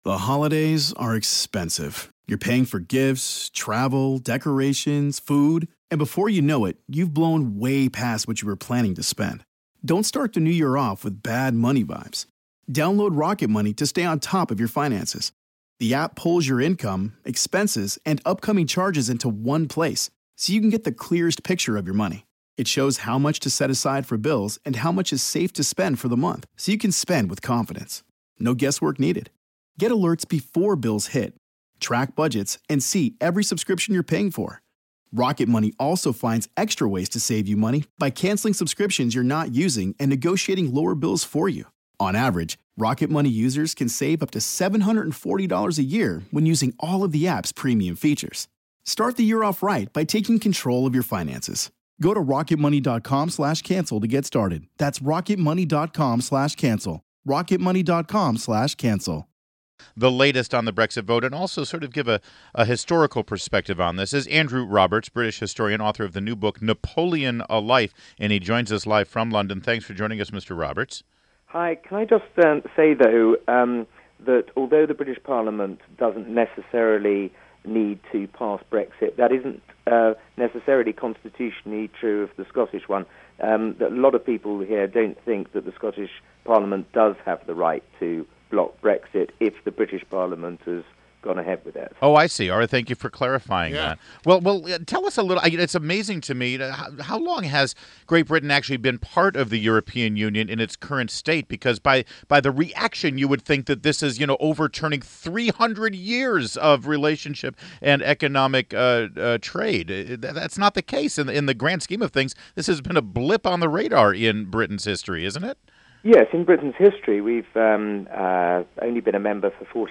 WMAL Interview - Andrew Roberts - 06.27.16